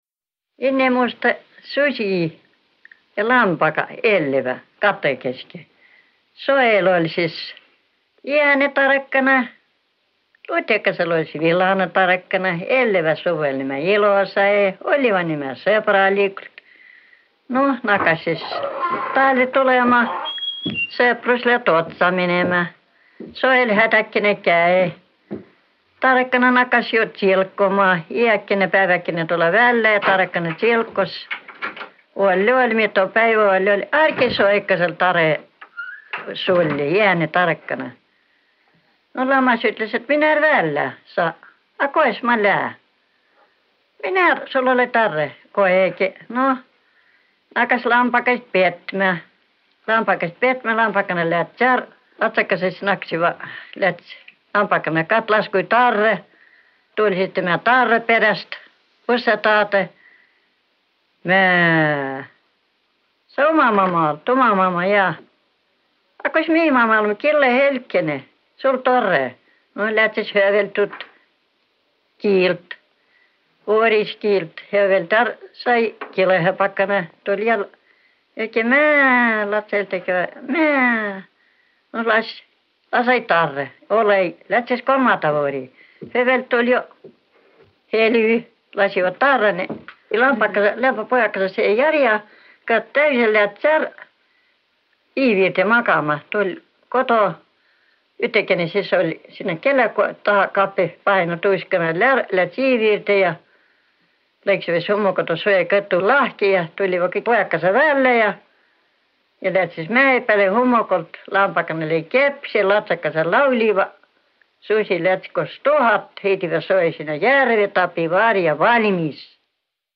Muinasjutt “Innemuistõ susi lambaga elliva’ katõkõistõ”
Seto kiil